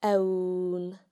The broad nn can be heard in ceann (a head):